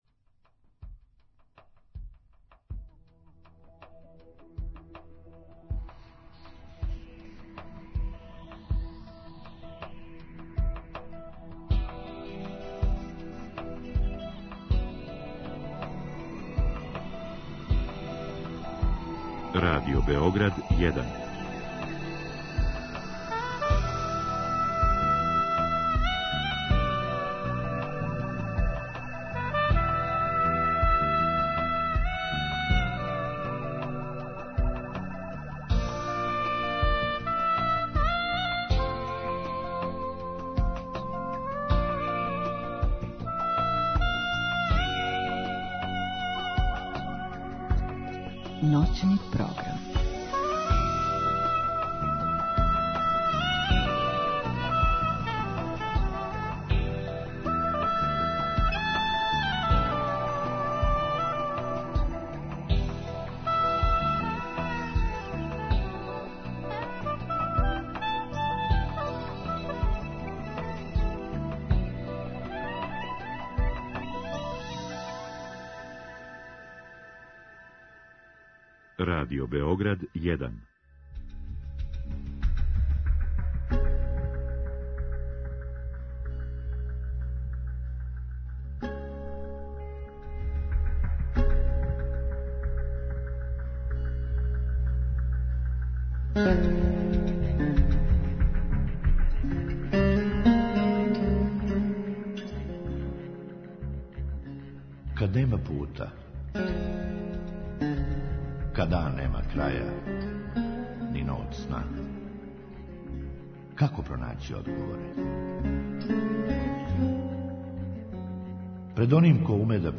Drugi sat je rezervisan za slušaoce, koji u direktnom programu mogu postaviti pitanje gošći.